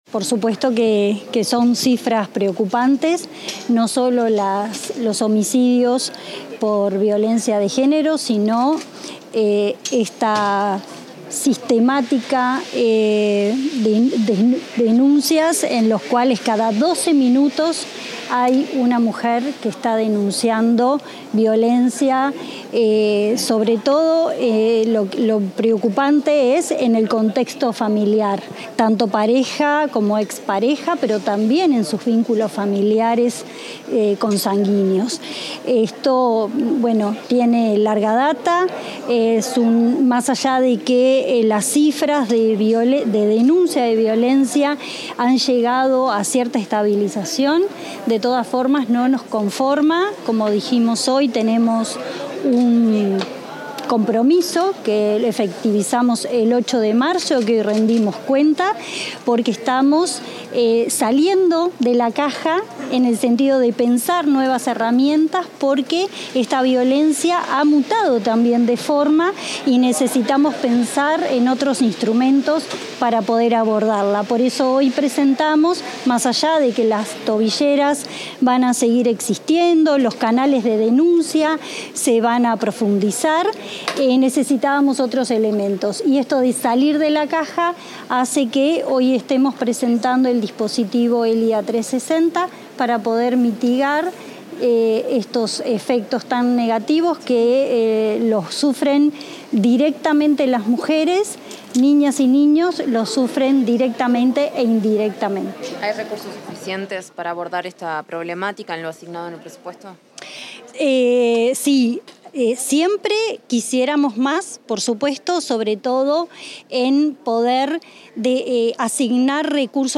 Declaraciones de la subsecretaria del Interior, Gabriela Valverde
Tras la presentación de datos sobre violencia basada en género, la subsecretaria del Interior, Gabriela Valverde, efectuó declaraciones a la prensa.